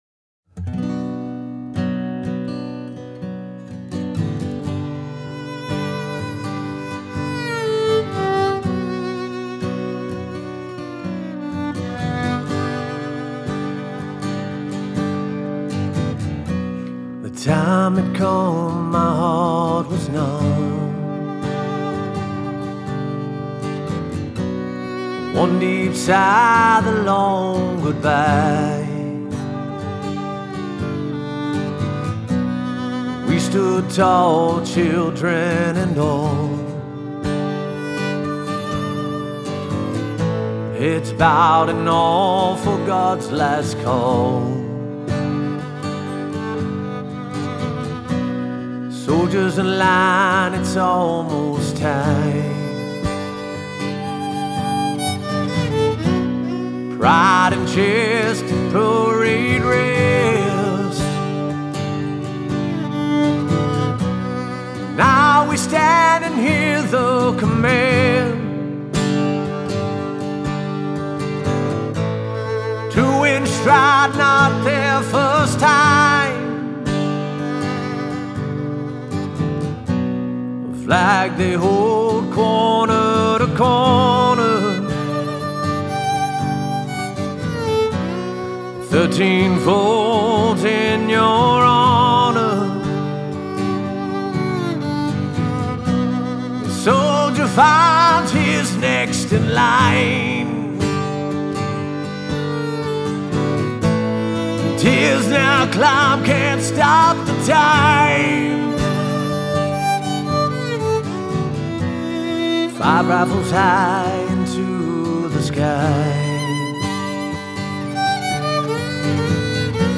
Vocals
Violin